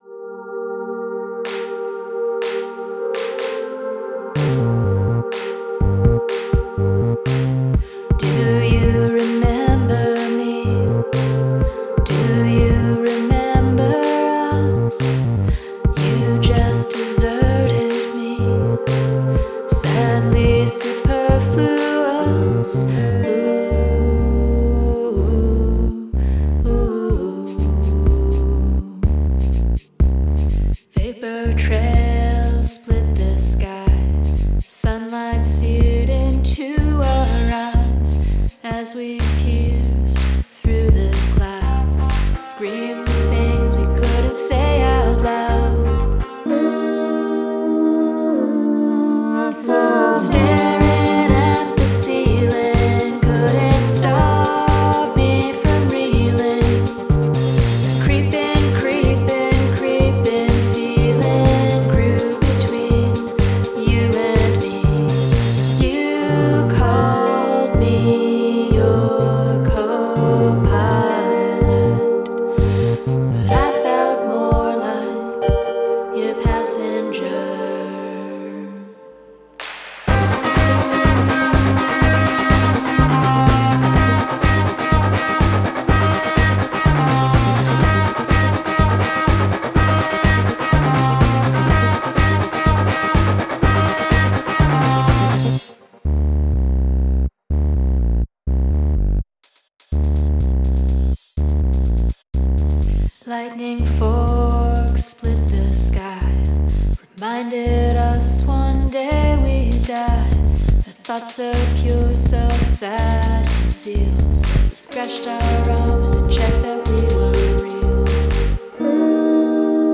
Guest Lead Vocals
Nice dynamics in this song.